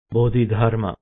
Buddhist vocabulary pronunciation